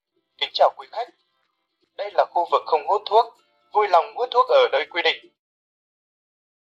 am-thanh-day-la-khu-vuc-khong-hut-thuoc-vui-long-hut-thuoc-o-noi-quy-dinh-www_tiengdong_com.mp3